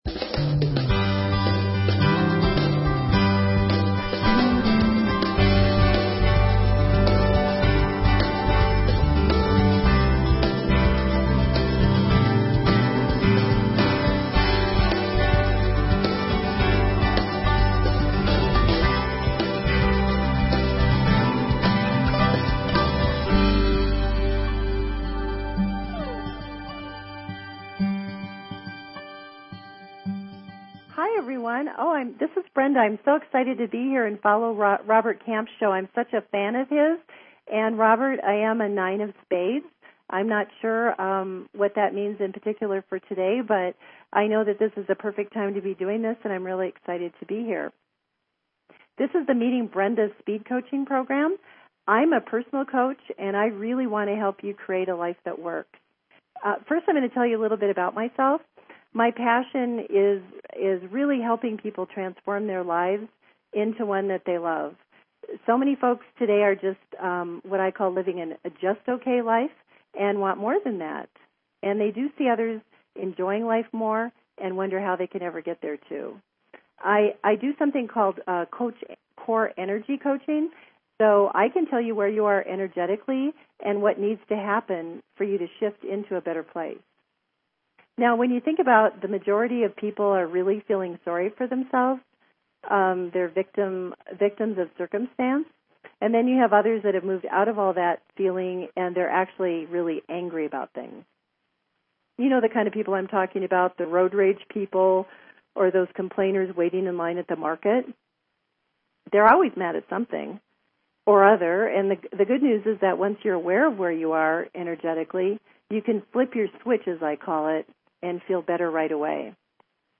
Talk Show Episode
do speed coaching sessions with callers!